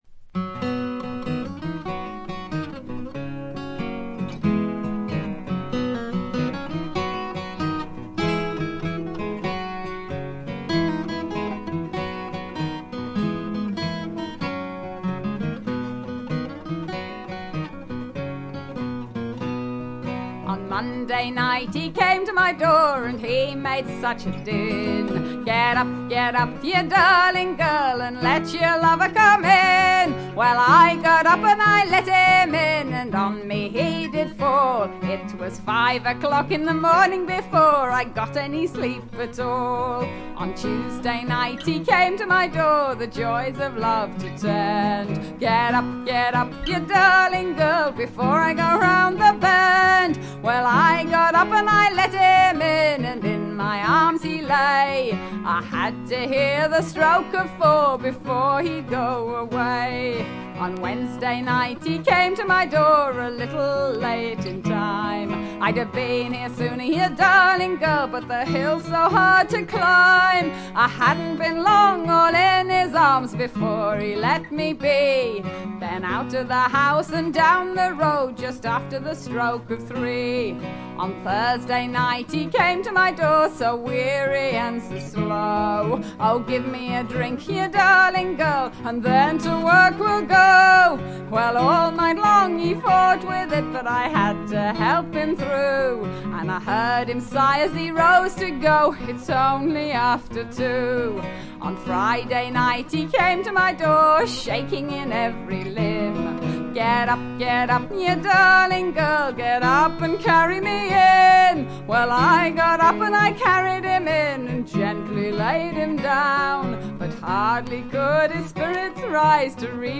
強靭なヴォーカルが他を寄せ付けぬ圧倒的な存在感を見せつけてくれます。
vocals
dulcimer, fiddle, viola, guitar